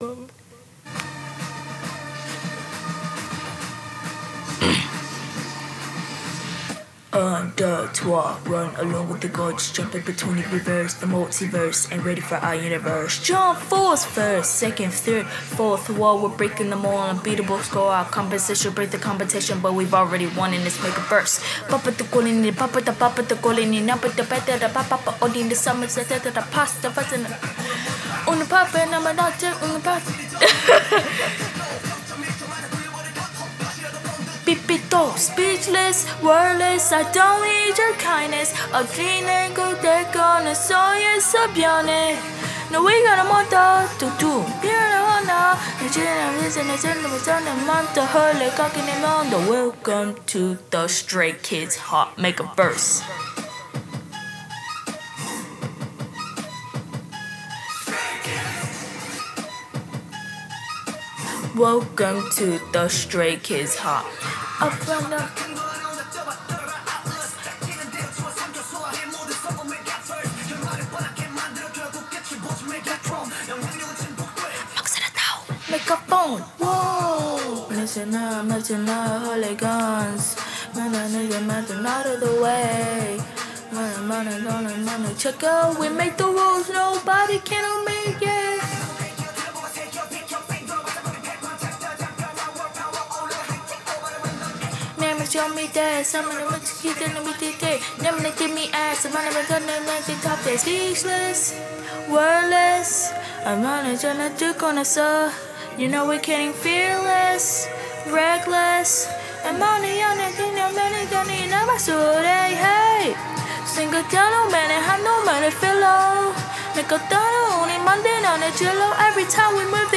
That’s why i skipped the Raps.